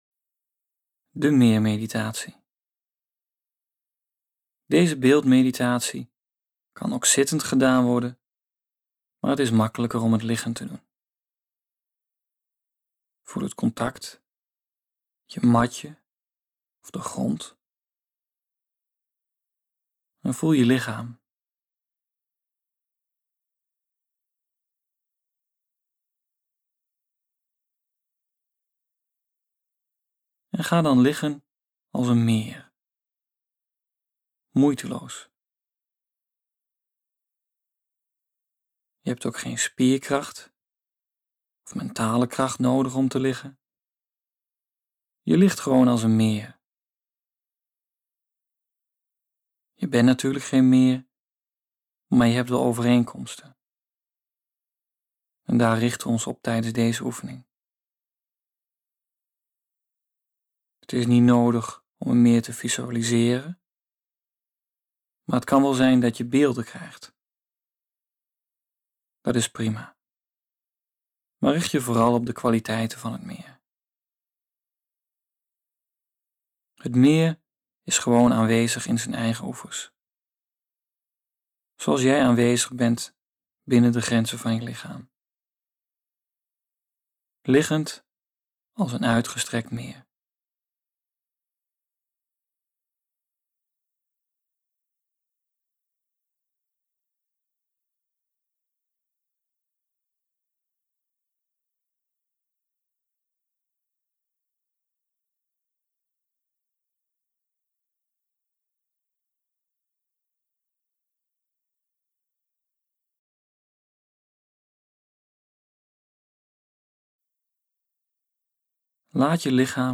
9-Meermeditatie-17.57-minuten.mp3